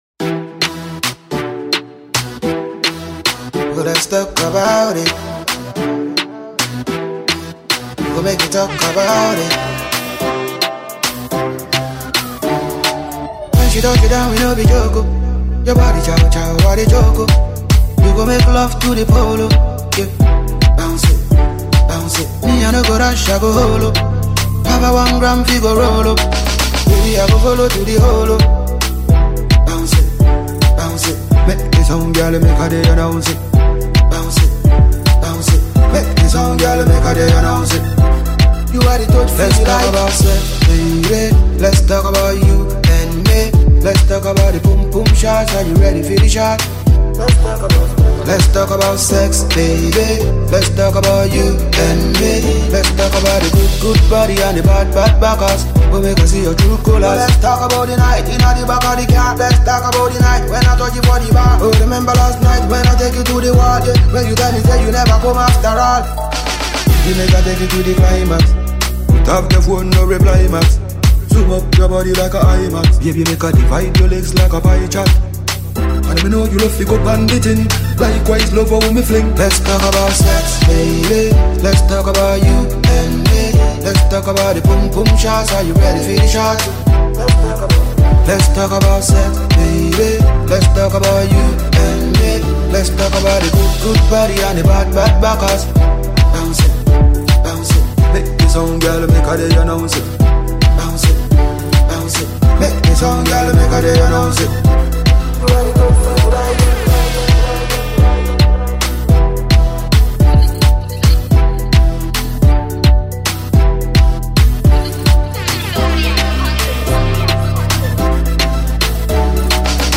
and it’s an uptempo dance tune by the dancehall singer.